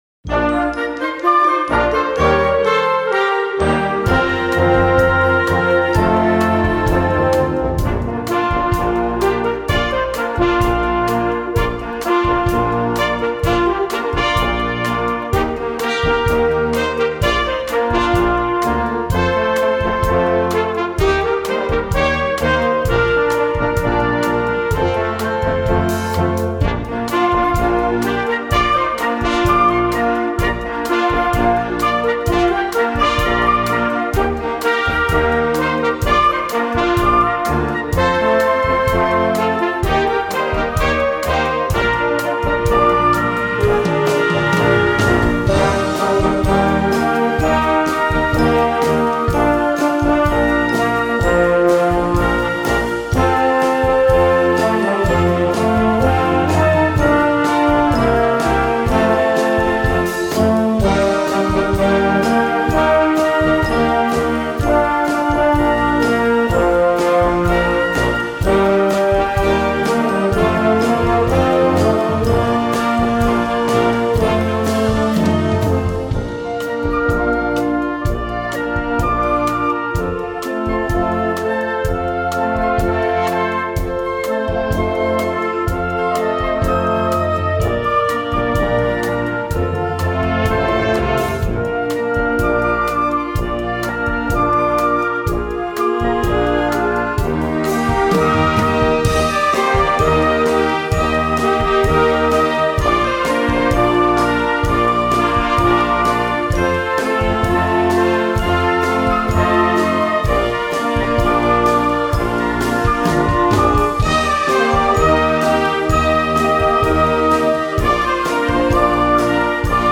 Gattung: Konzertstück für Jugendblasorchester
Besetzung: Blasorchester